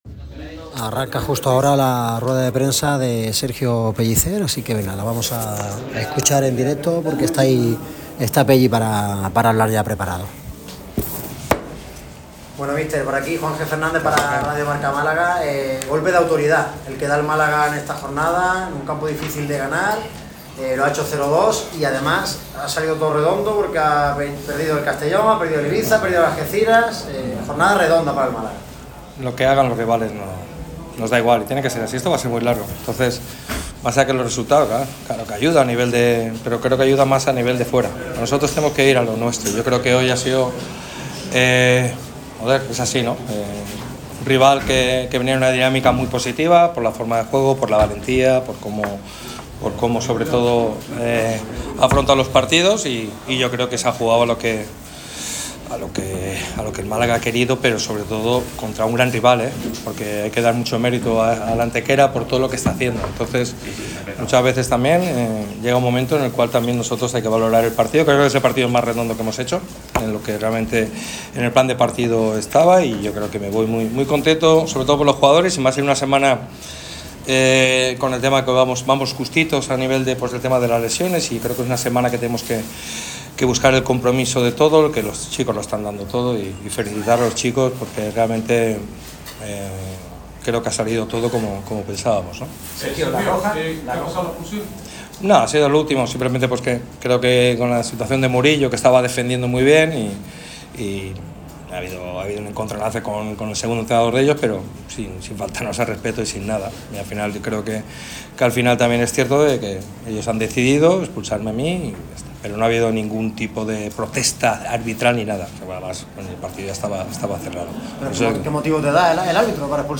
El técnico de Nules ha comparecido ante los medios tras la victoria de los boquerones en el Nuevo El Maulí 0-2 gracias a los tantos de Genaro y Kevin. Pellicer fue sorprendentemente expulsado con el tiempo cumplido por protestar y sobre ello también ha opinado.